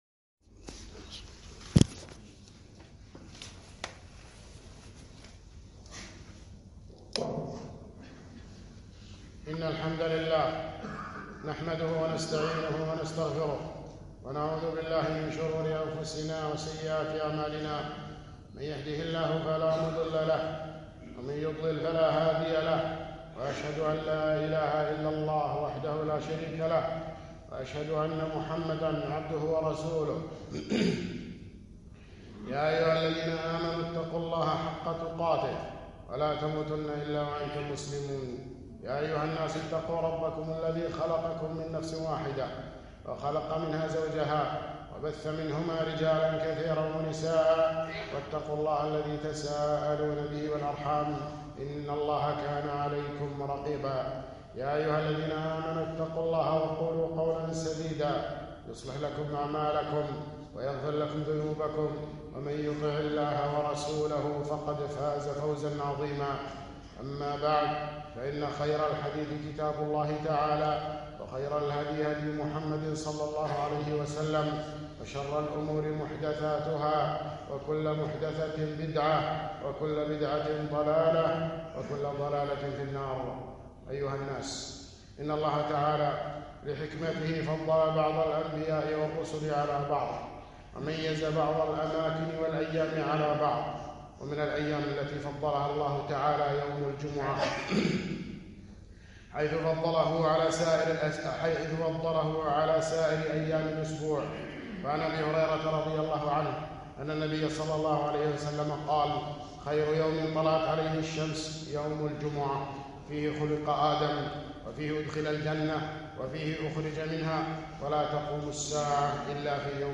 خطبة - فضل يوم الجمعة وأحكامه